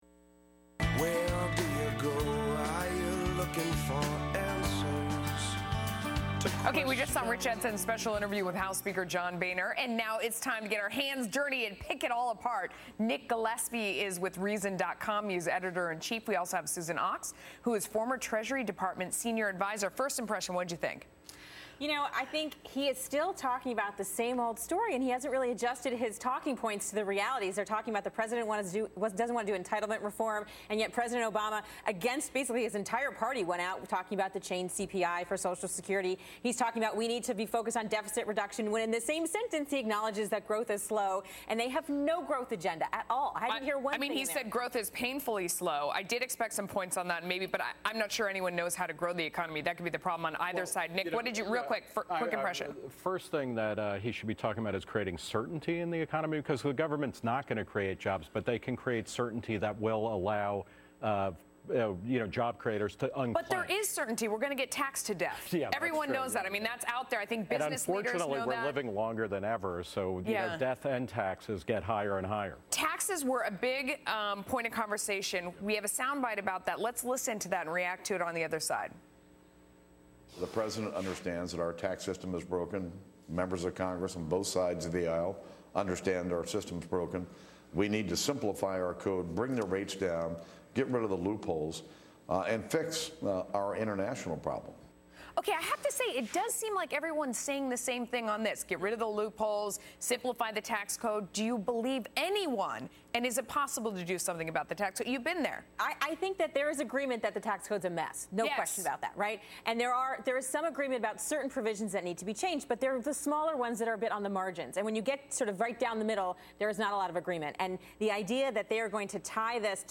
Reason TV Editor-in-Chief Nick Gillespie appeared on Fox Business' Money with Melissa Francis to discuss House Speaker John Boehner, the disastrous U.S. tax code, and the future of the GOP.